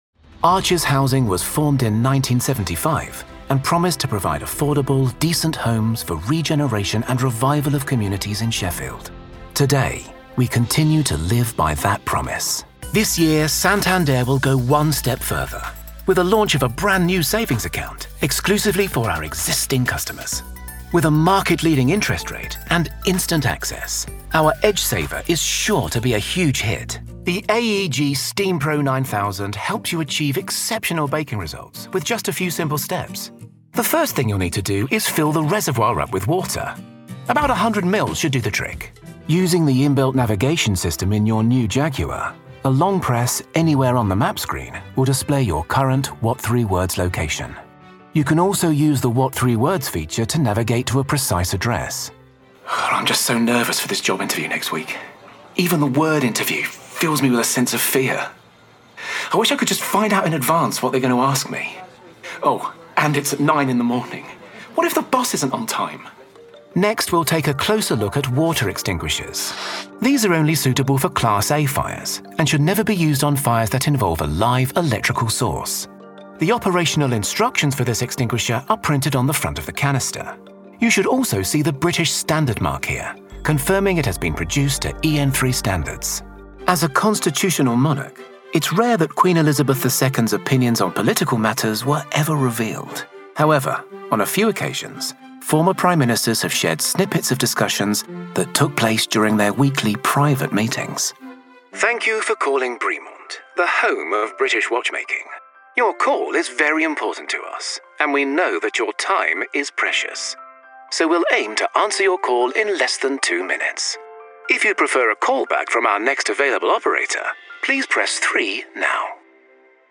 Corporate Videos
Session Booth / Neumann TLM103 / Audient iD4 / MacBook Pro / Adobe Audition
BaritoneBassDeepLow
TrustworthyAuthoritativeWarmConversationalFriendly